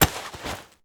foley_combat_fight_grab_throw_02.wav